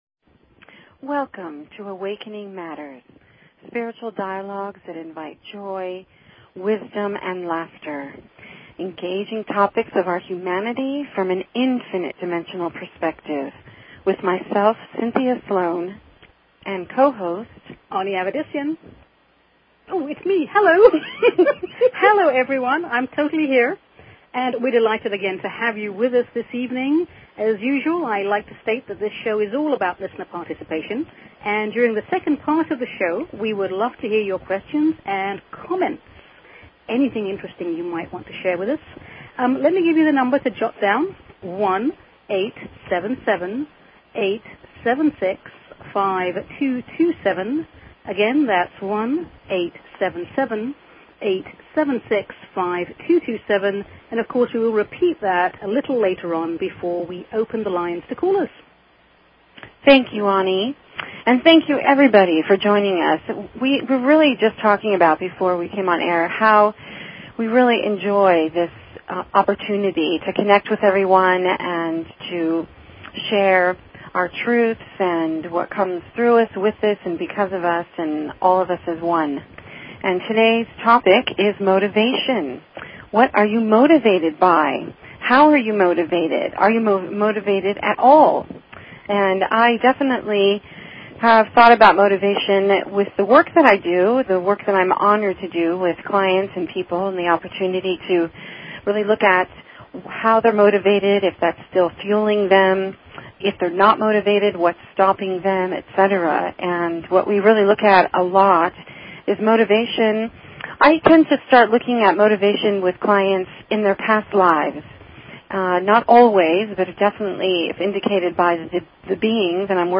Talk Show Episode, Audio Podcast, Awakening_Matters and Courtesy of BBS Radio on , show guests , about , categorized as
A spiritual dialogue that invites divine wisdom, joy and laughter. Engaging topics of our humanity from an infinite dimensional perspective.